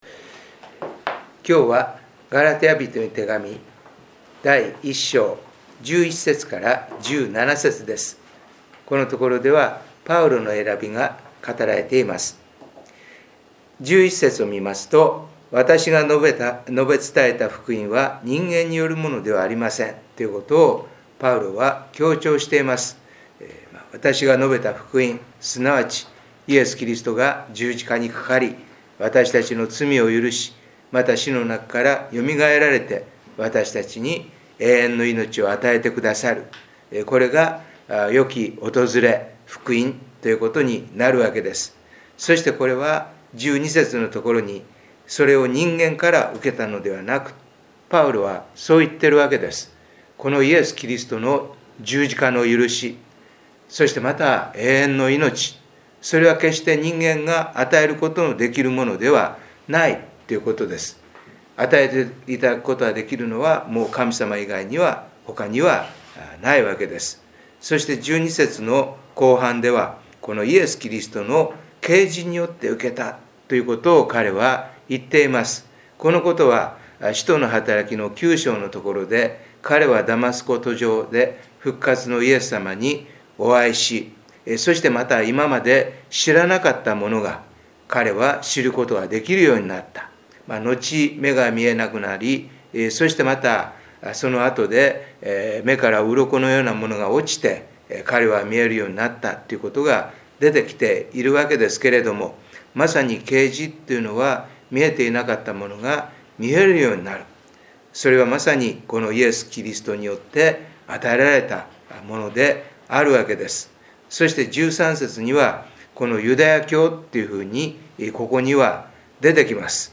2021年6/13 第二主日礼拝
音声メッセージです。